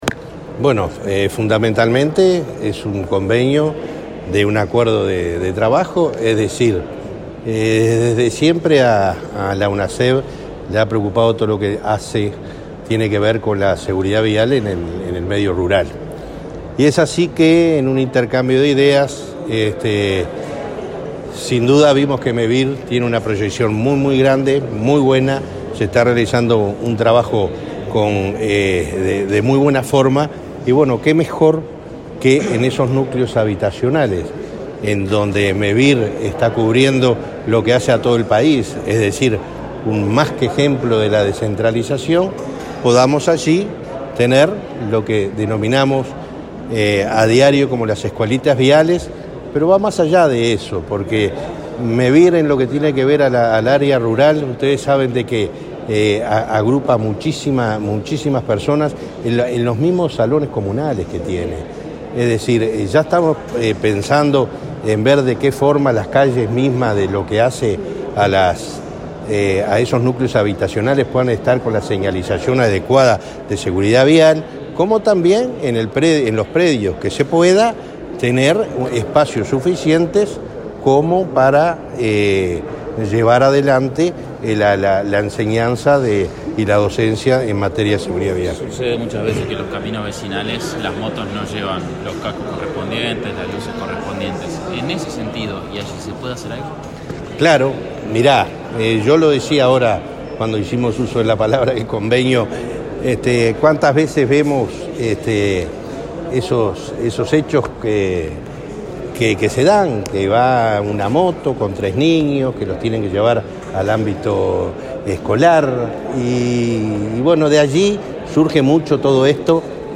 Declaraciones del presidente de Unasev, Alejandro Draper, a la prensa